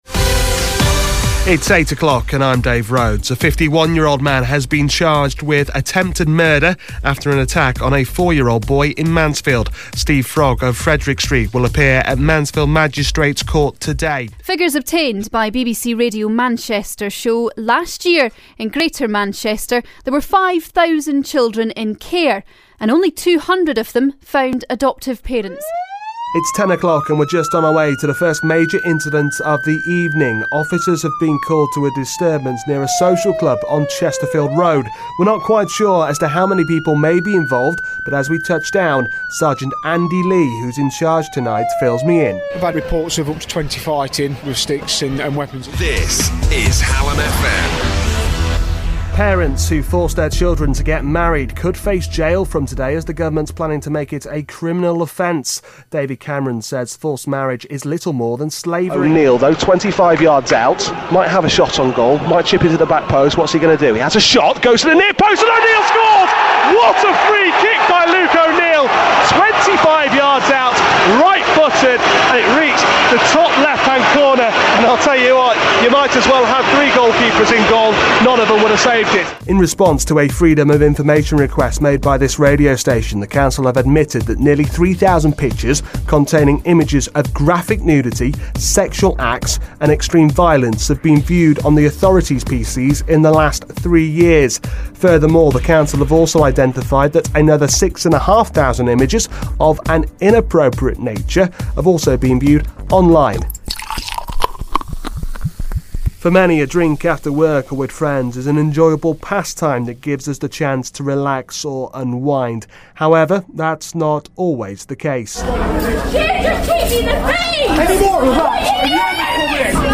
Examples of my work as a radio reporter, newsreader and football commentator.